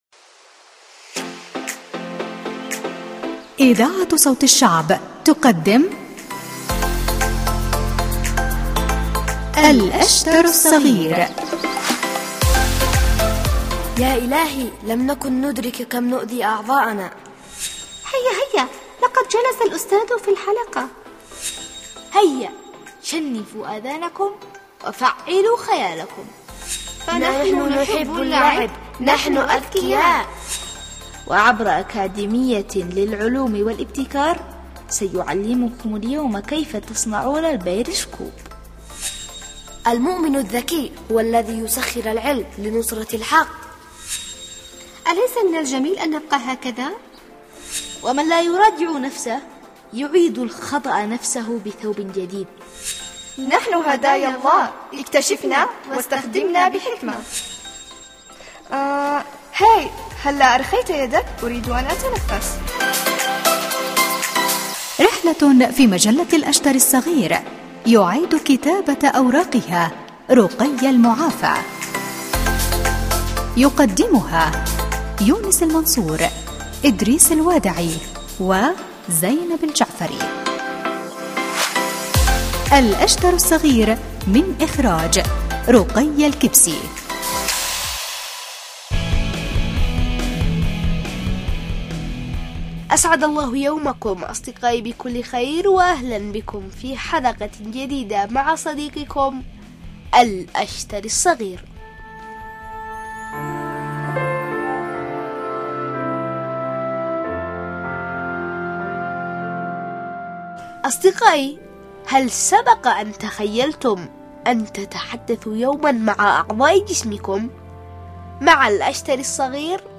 برامج الأطفال